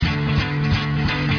GUITAR LOOPS - PAGE 1 2 3 4
BOOGIE (118Kb)